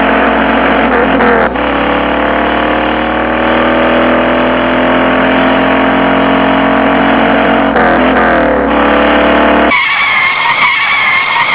Sons urbanos 35 sons
carrocorrida.wav